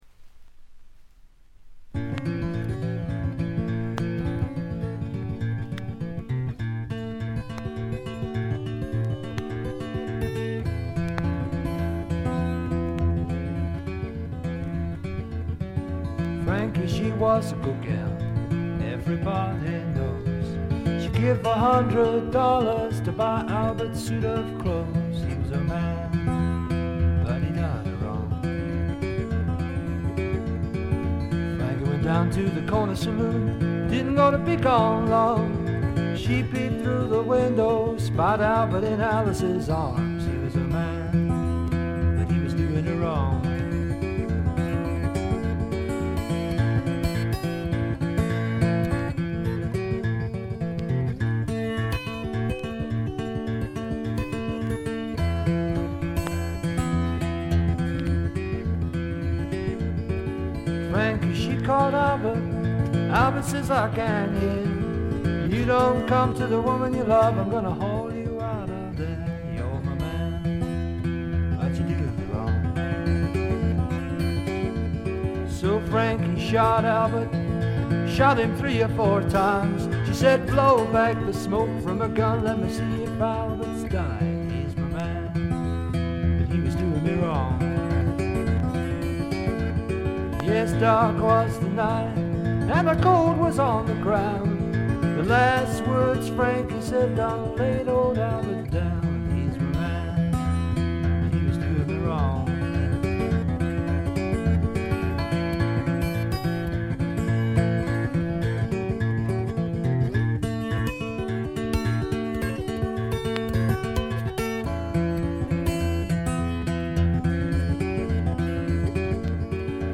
ところどころでチリプチ（特にB2は目立ちます）。鑑賞を妨げるようなノイズはありません。
試聴曲は現品からの取り込み音源です。